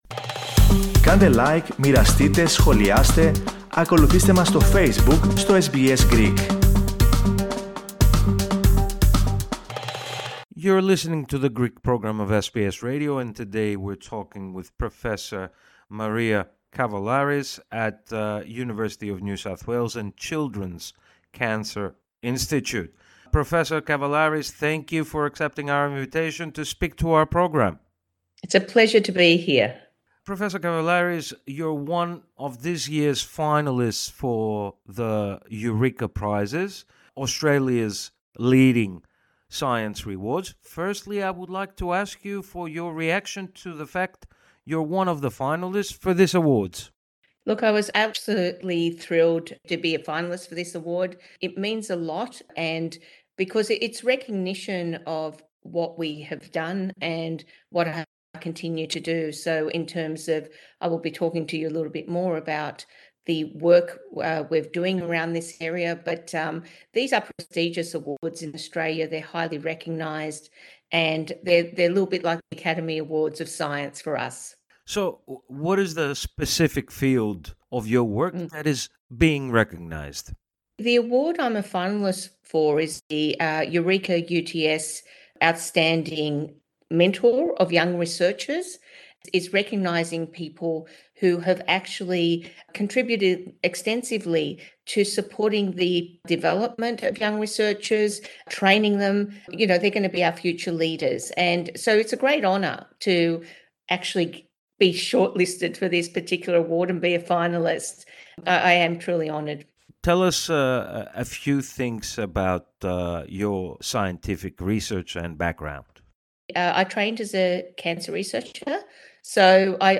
η οποία μίλησε στο Ελληνικό Πρόγραμμα της ραδιοφωνίας SBS.